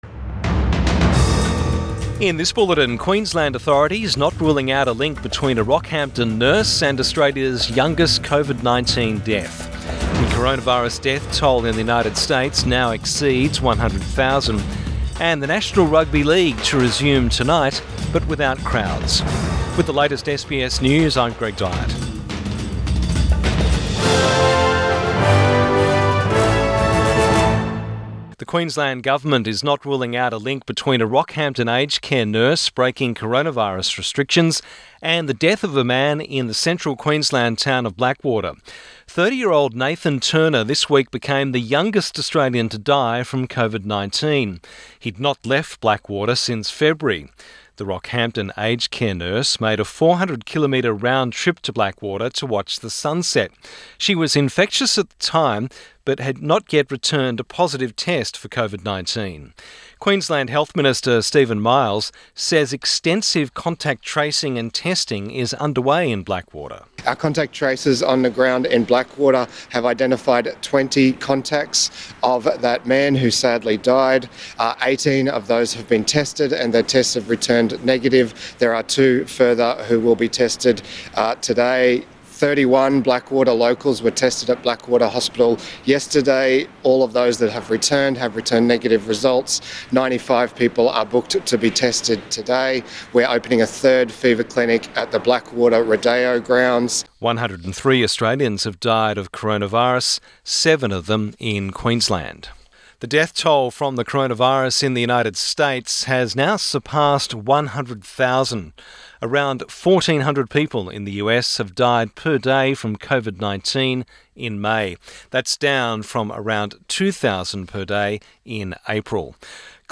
Midday bulletin 28 May 2020